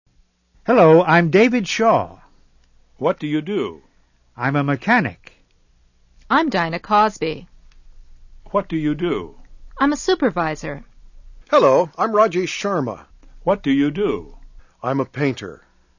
はつおんれんしゅうEnglish USA Lesson 3　Part 1-2